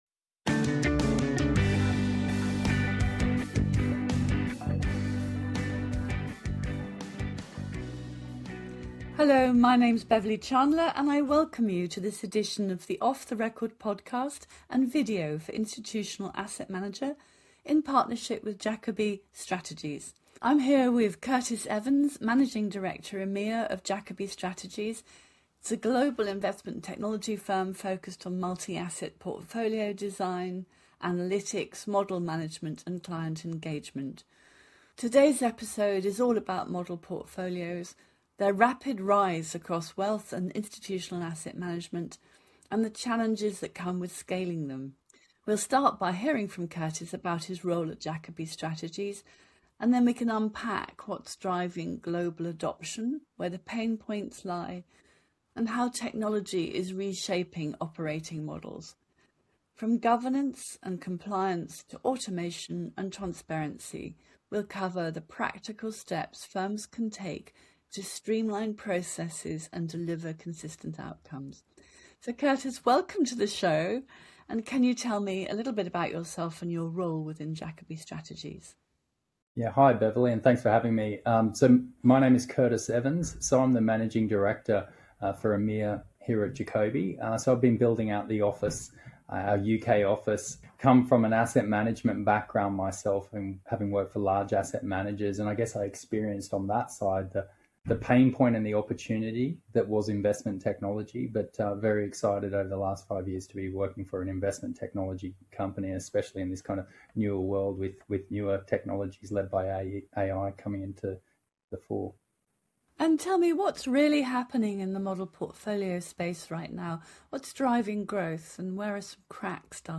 Visit Institutional Asset Manager to watch the interview.